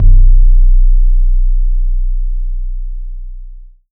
BLUE BASS -L.wav